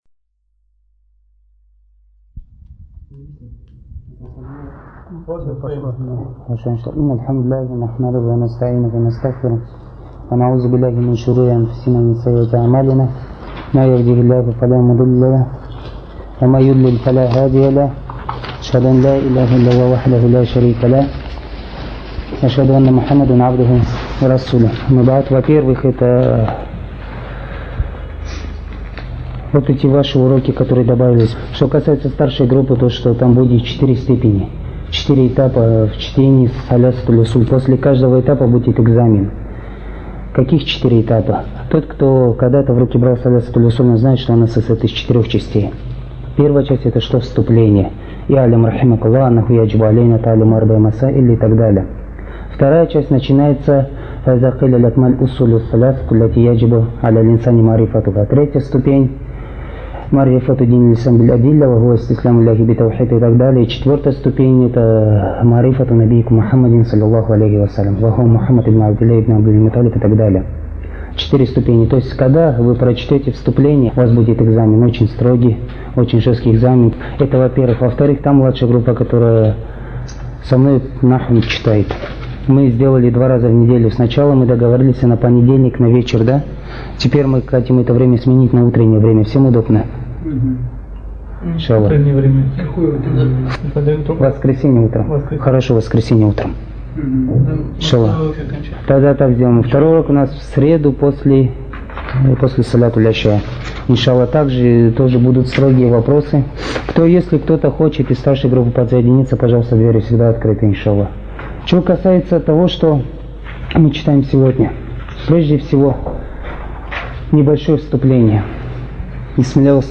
Материал:Лекция шейха Салиха бин АбдульАзиз Содержание:описание каким образом следует требовать знания